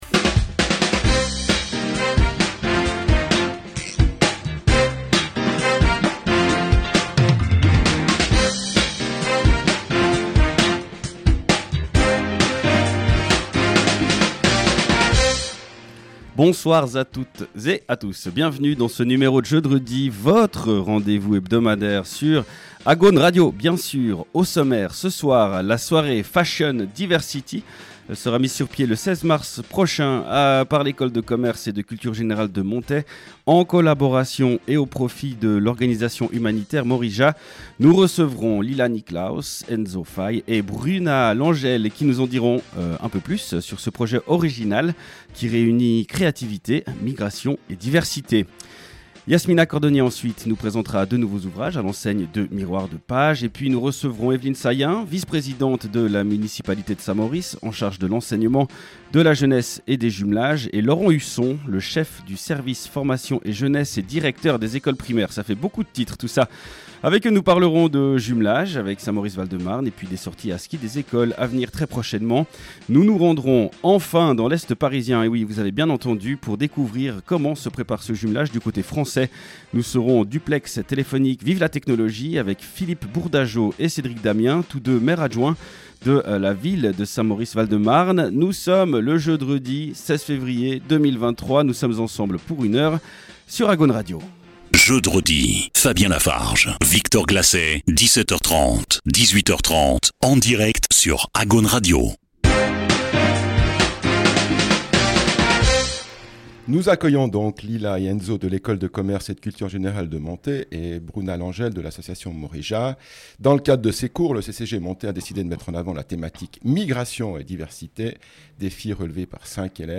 Et un duplex téléphonique nous emmène dans l'est parisien retrouver Philippe Bourdajaud et Cédric Damien, maires-adjoints de notre ville jumelle de Saint-Maurice Val-de-Marne, à quelques jours de la venue à Saint-Maurice des jeunes mauritiens.